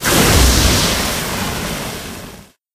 amber_oil_burn_01.ogg